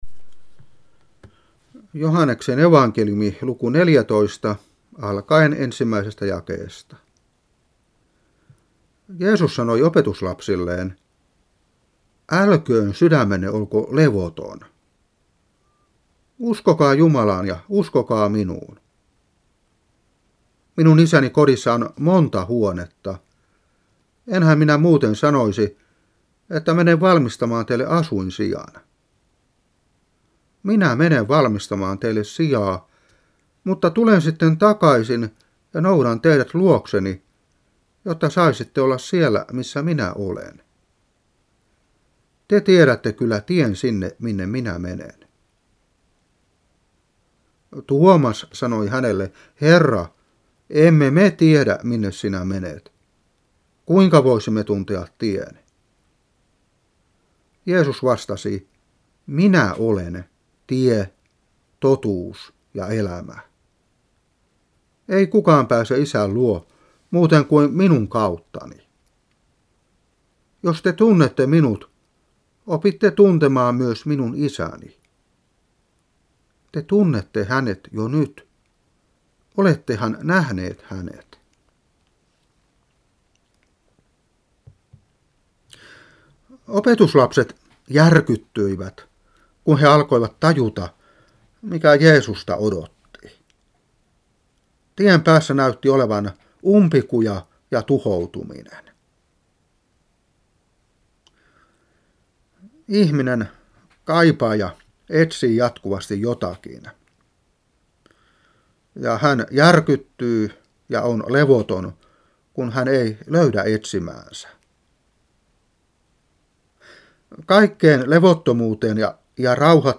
Saarna 1998-5.